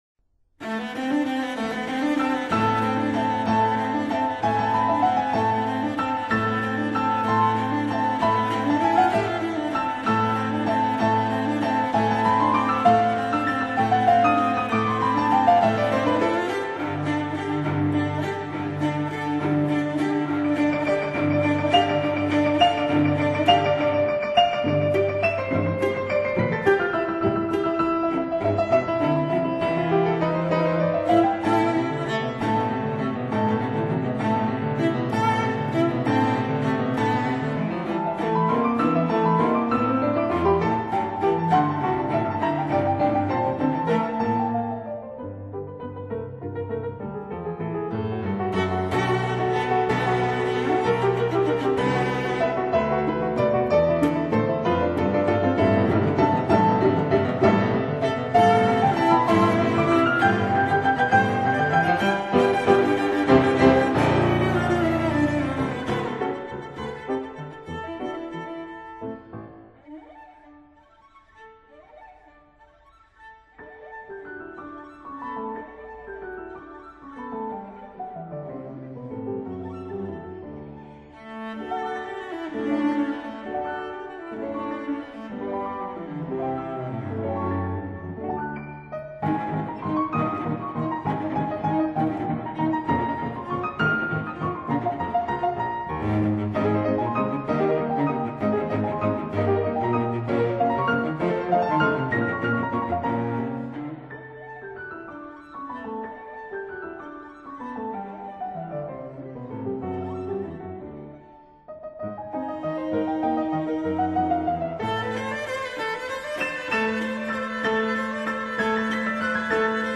分辑信息：CD20-22 室内乐